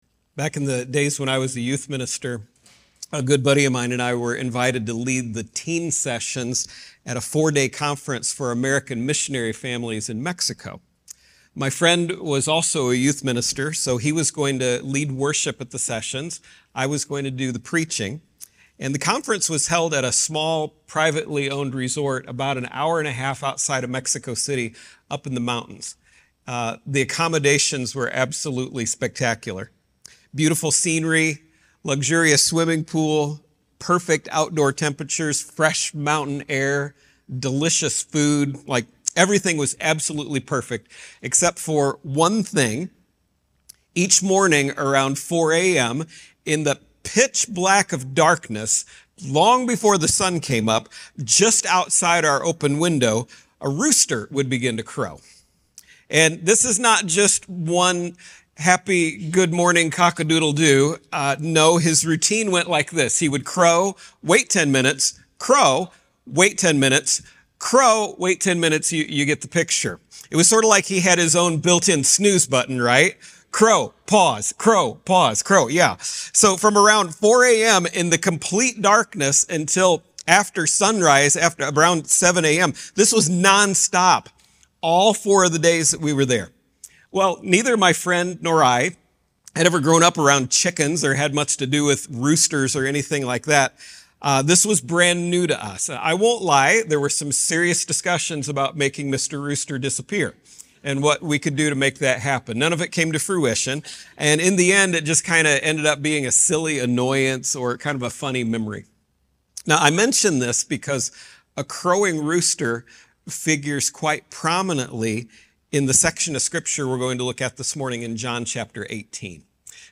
Sermons | First Christian Church